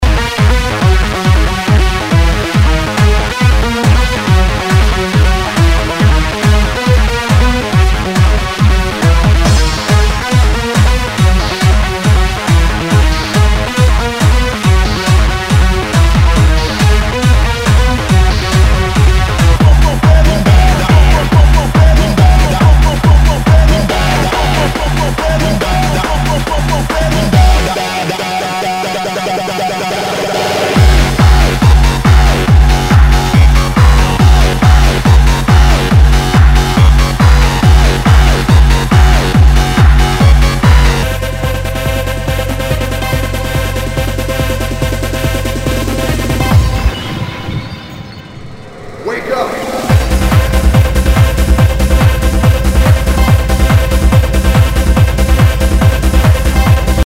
HOUSE/TECHNO/ELECTRO
ナイス！トランス！